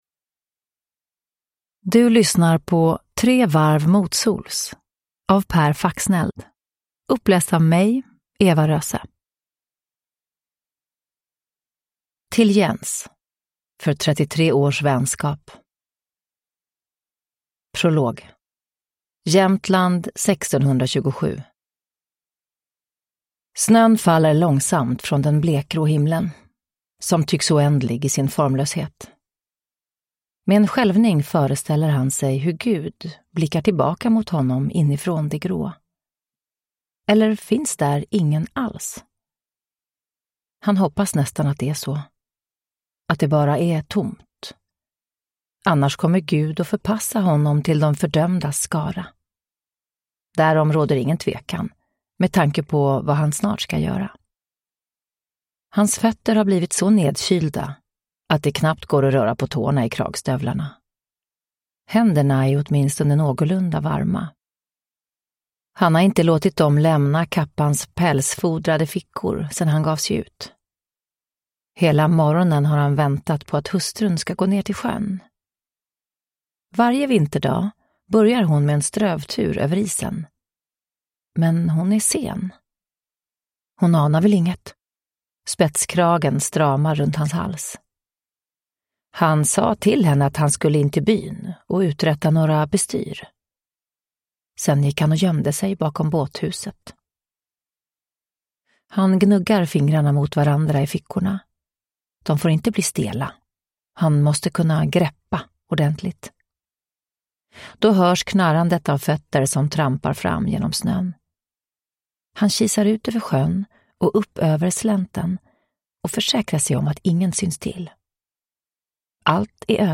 Tre varv motsols – Ljudbok
Uppläsare: Eva Röse